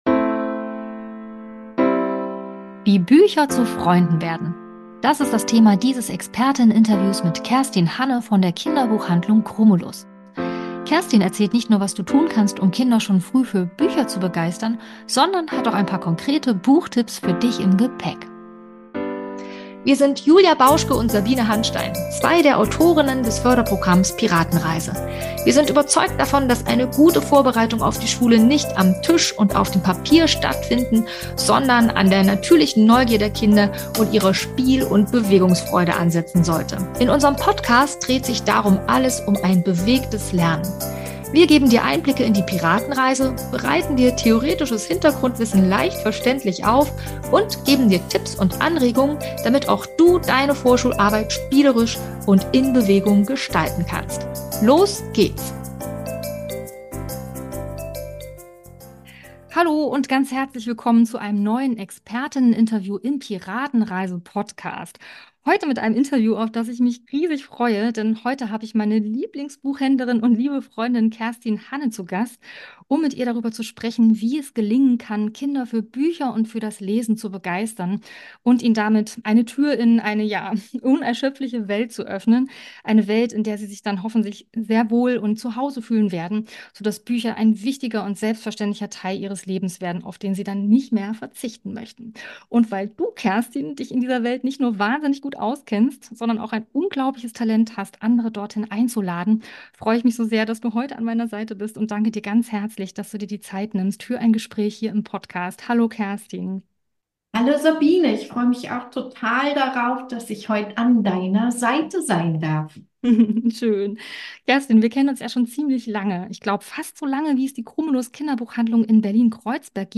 #72 - Expertinnen-Interview: Wie Bücher zu Freunden werden ~ Piratenreise Podcast